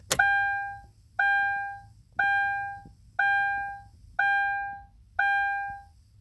door chime kia
beep bell car chime ding door-chime kia tone sound effect free sound royalty free Sound Effects